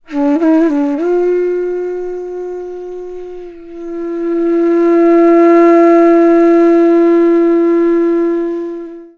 FLUTE-A02 -R.wav